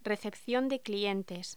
Locución: Recepción de clientes
Sonidos: Voz humana Sonidos: Hostelería